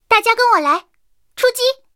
三号出击语音.OGG